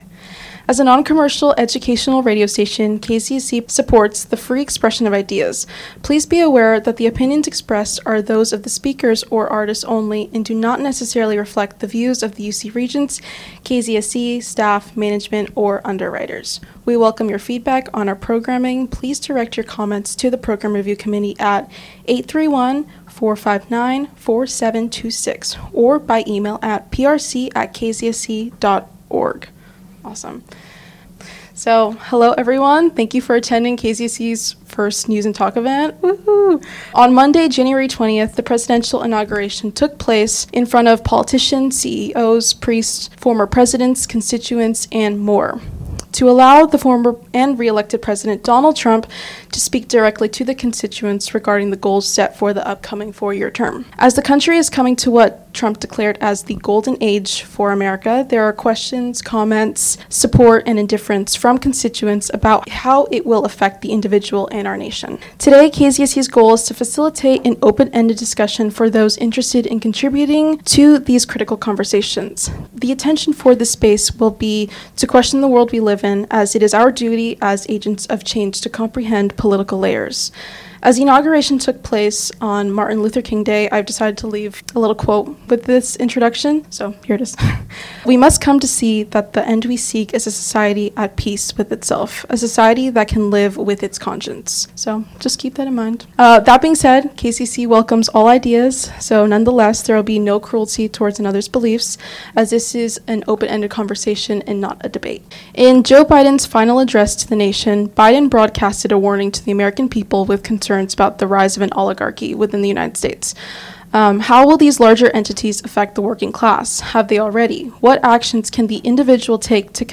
DISCLAIMER: KZSC welcomes all ideas; nonetheless, there will be no cruelty towards another’s beliefs as this is an open-ended conversation, NOT a debate!